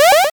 Powerup13
Category 🎮 Gaming
8-bit arcade atari chiptune game jump life mario sound effect free sound royalty free Gaming